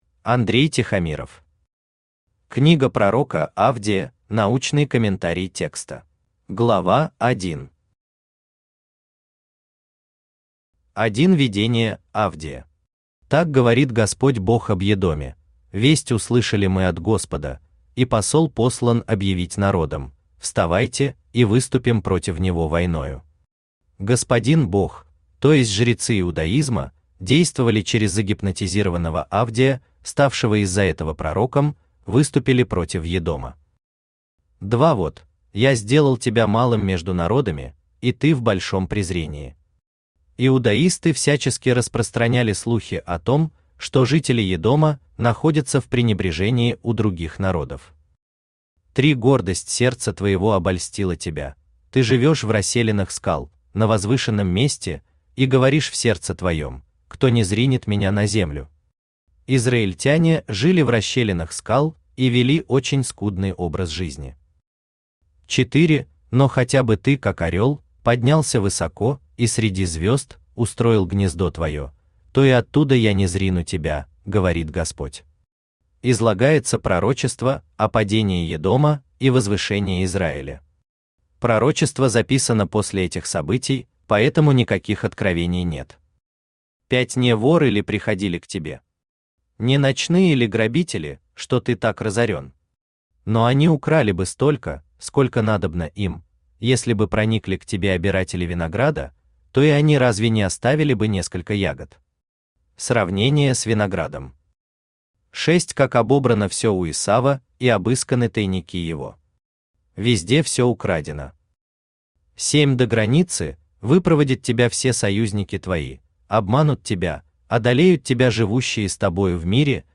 Читает аудиокнигу Авточтец ЛитРес.